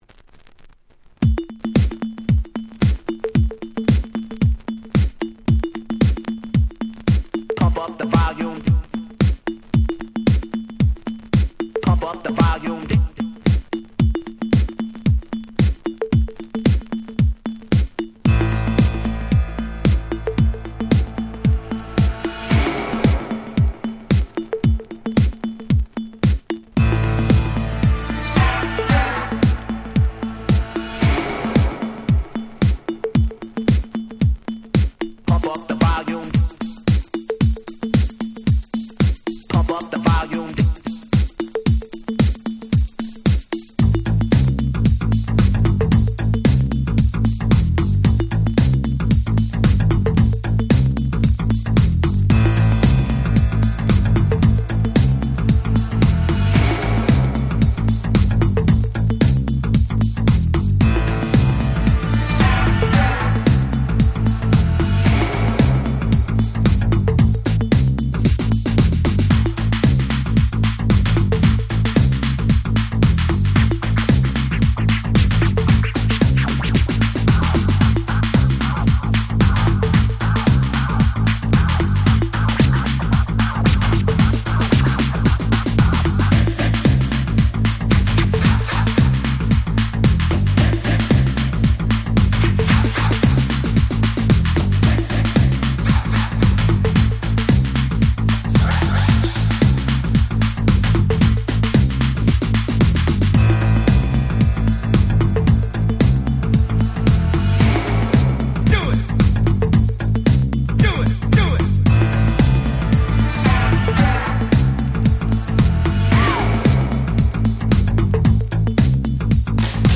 87' 世界的スーパーヒットDance Music !!
Houseの走りと言うかBreak Beatsと言うか、、、でも超格好良い！！
ハウス ブレイクビーツ